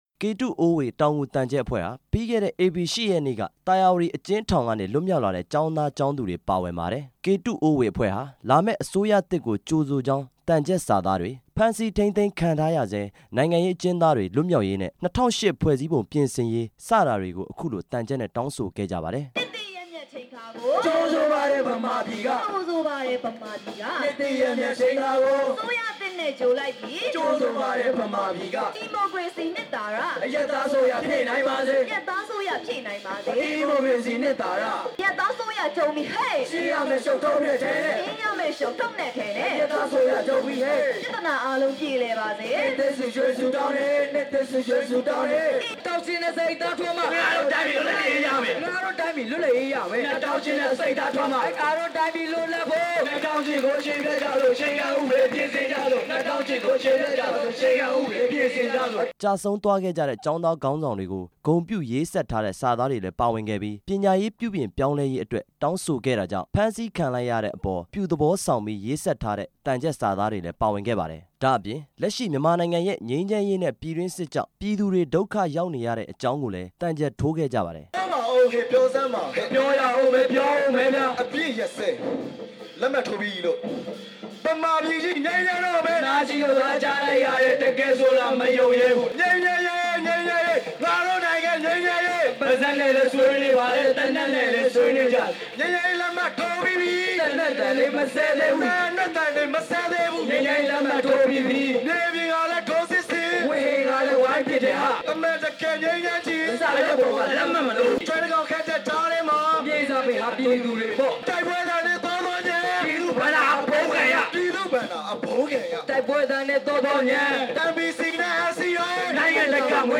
ဗမာနိုင်ငံလုံးဆိုင်ရာ ကျောင်းသား သမဂ္ဂများအဖွဲ့ချုပ်က တည်ထောင်ထားတဲ့ ကေတုအိုးဝေ (တောင်ငူ)က သြင်္ကန်သံချပ် အဖွဲ့ဟာ ရန်ကုန်မြို့ ဗိုလ်တထောင် မြို့နယ် က ဗမာသစ်မဏ္ဍပ်မှာ မဟာသြင်္ကန် ဒုတိယအကြတ်နေ့ မနေ့က  ဖျော်ဖြေခဲ့ပါတယ်။